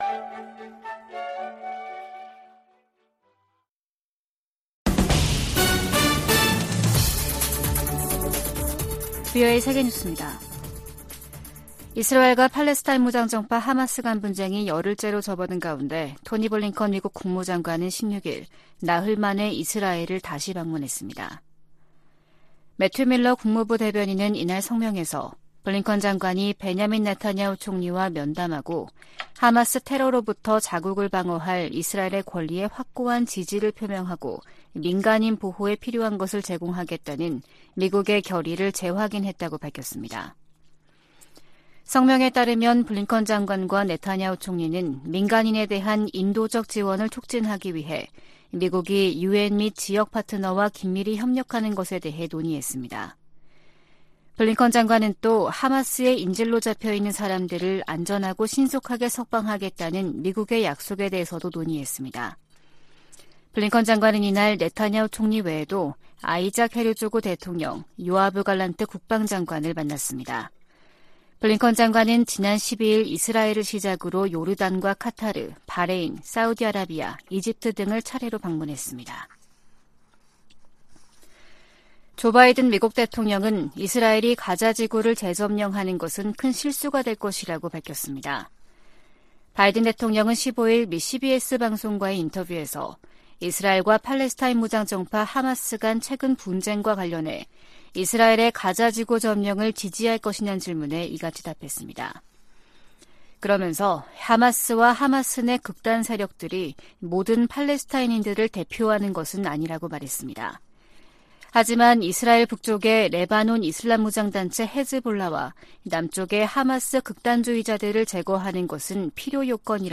VOA 한국어 아침 뉴스 프로그램 '워싱턴 뉴스 광장' 2023년 10월 17일 방송입니다. 북한이 지난달 컨테이너 1천개 분량의 군사장비와 탄약을 러시아에 제공했다고 백악관이 밝혔습니다. 미국 정부가 북러 무기 거래 현장으로 지목한 항구에서 계속 선박과 컨테이너의 움직임이 포착되고 있습니다. 줄리 터너 미 국무부 북한인권특사가 한국을 방문해 북한 인권 상황을 개선하기 위해 국제사회가 힘을 합쳐야 한다고 강조했습니다.